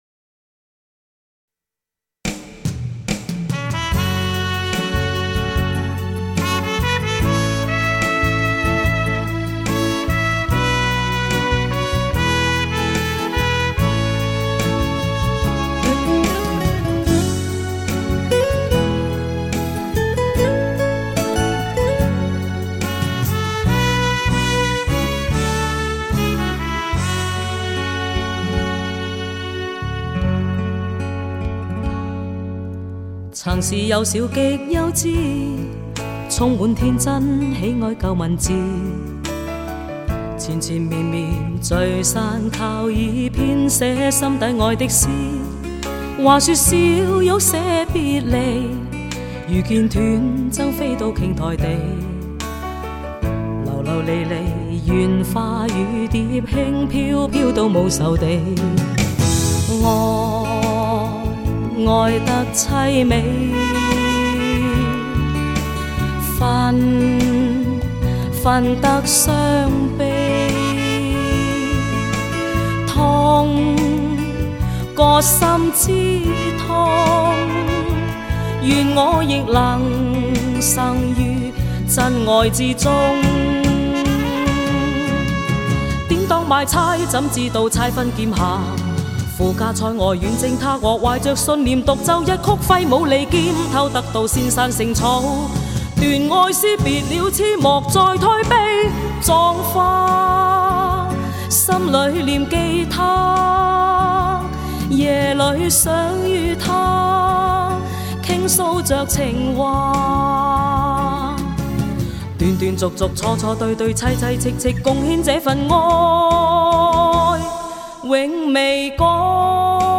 独特的唱腔，
深厚的演唱造诣，
发烧极品级的录音制作
人声格外暖厚磁性动人，
咬字清晰利落，